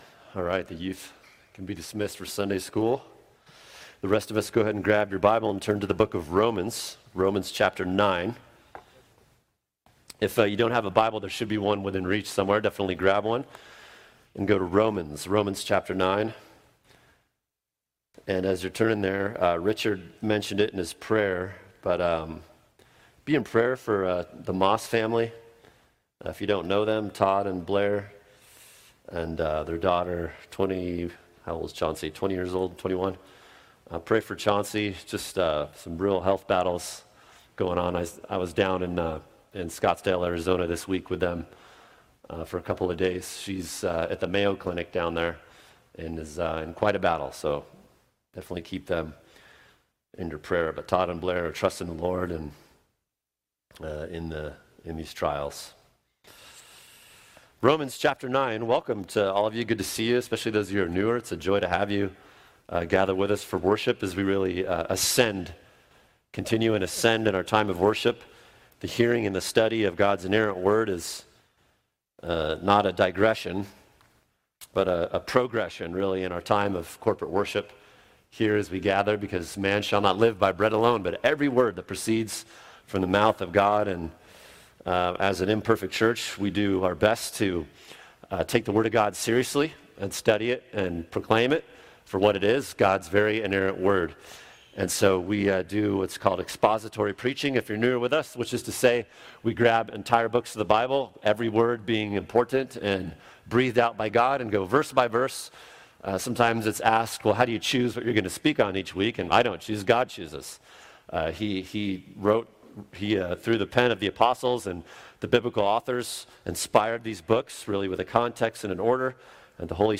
[sermon] Sovereign Election Romans 9:11-12 | Cornerstone Church - Jackson Hole